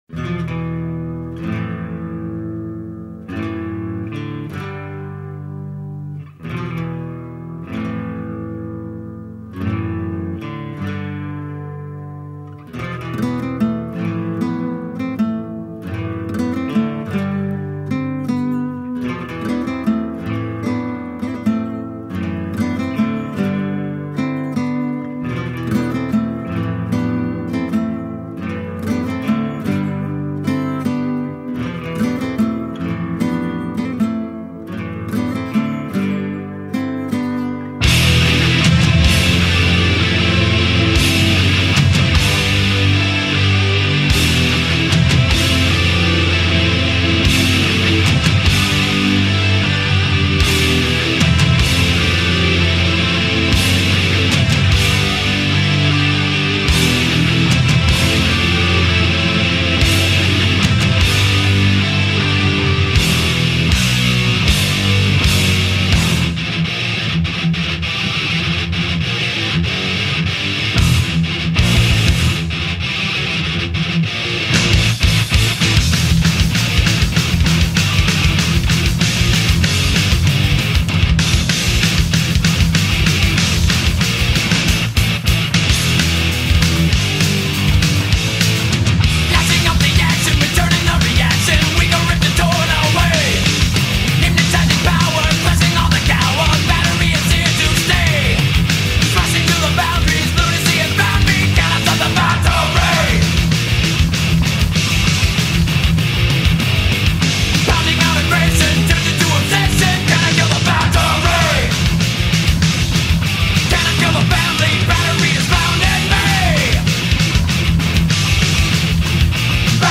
Thrash Metal, Heavy Metal